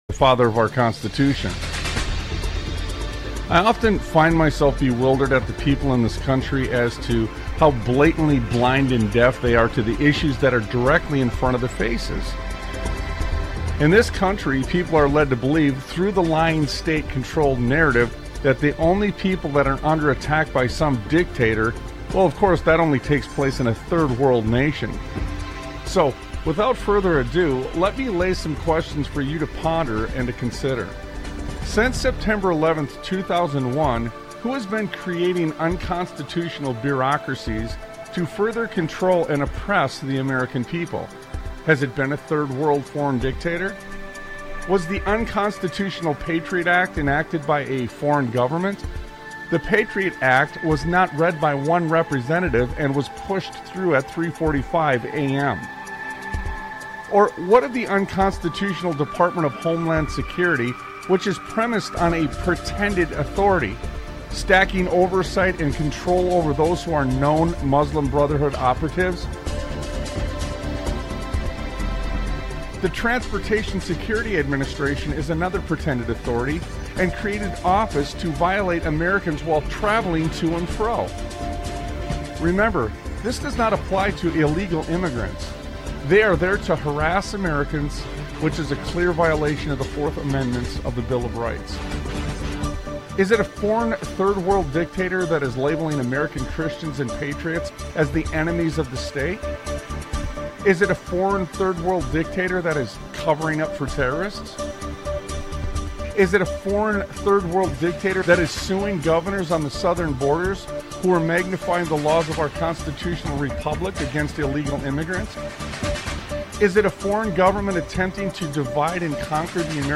Talk Show Episode, Audio Podcast, Sons of Liberty Radio and American Dream - Unscriptural? on , show guests , about American Dream - Unscriptural, categorized as Education,History,Military,News,Politics & Government,Religion,Christianity,Society and Culture,Theory & Conspiracy